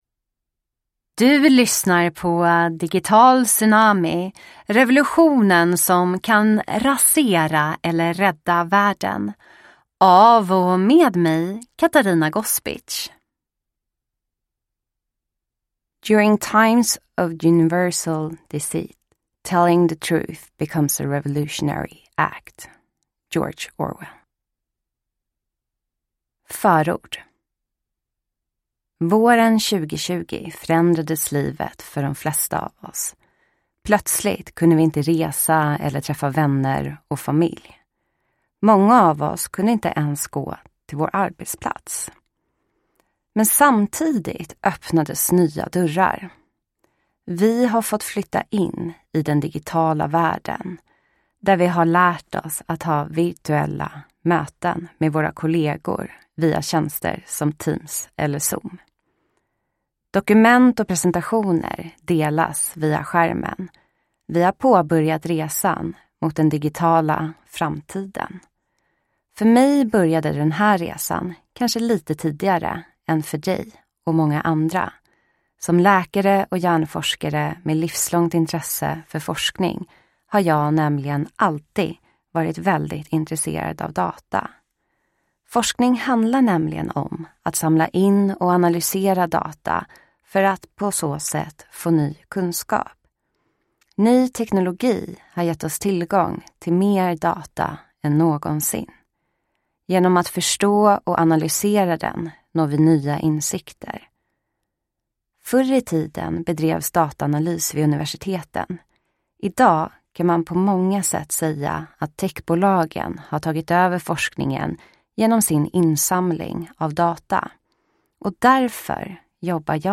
Digital tsunami : revolutionen som kan rasera eller rädda världen – Ljudbok – Laddas ner